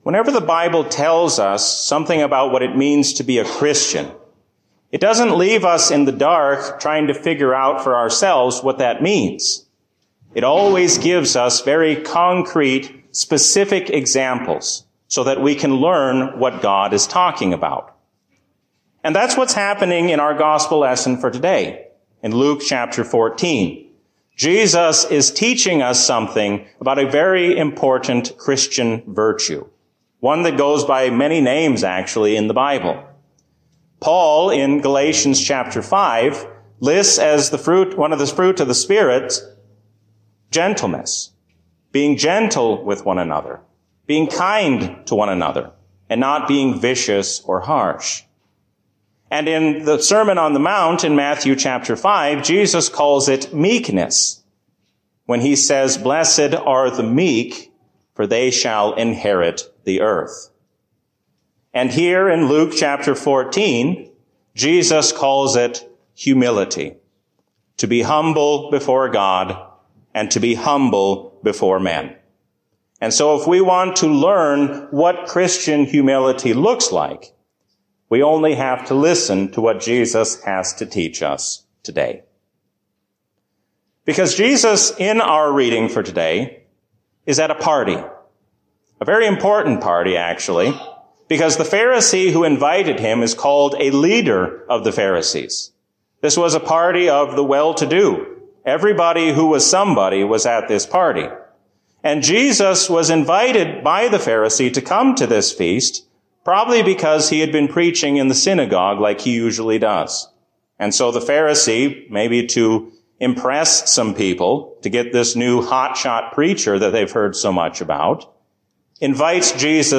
A sermon from the season "Trinity 2022." When we humble ourselves under the hand of God, then we have no reason to be anxious about the future.